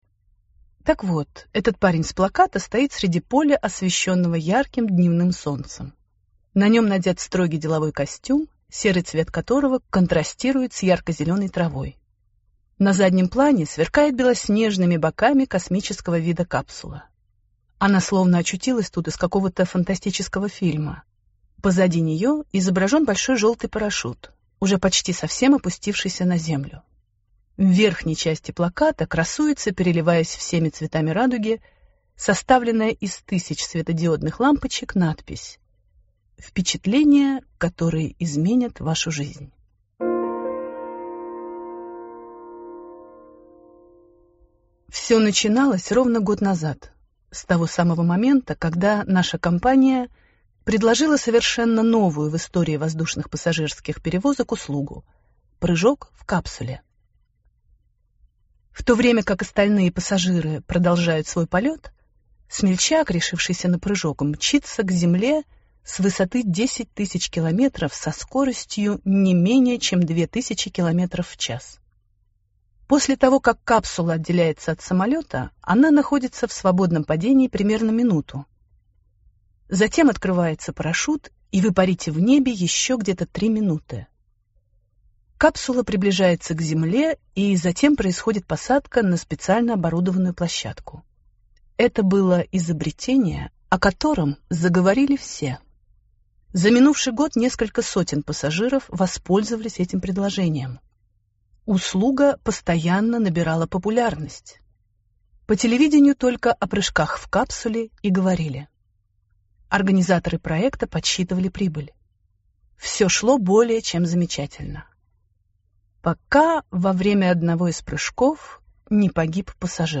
Аудиокнига Прыжок | Библиотека аудиокниг